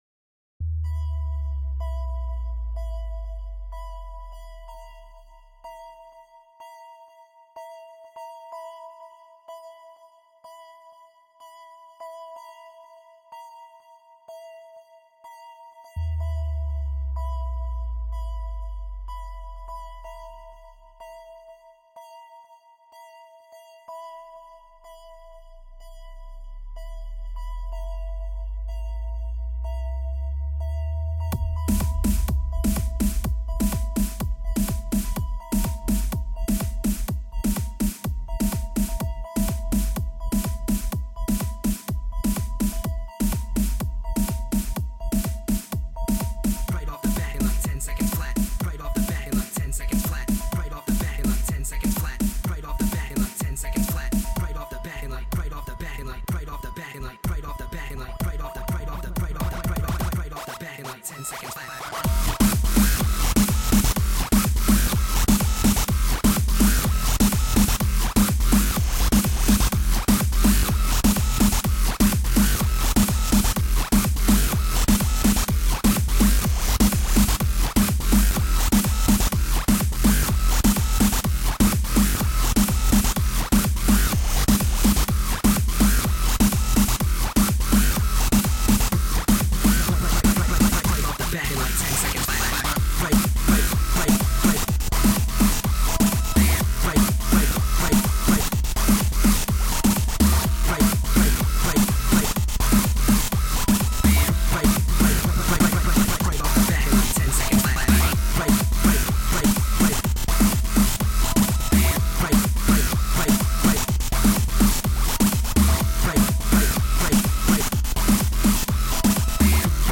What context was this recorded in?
I performed and debuted it at Canterlot Gardens 2012.